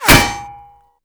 BulletImpact_Metal 02.wav